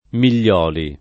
[ mil’l’ 0 li ]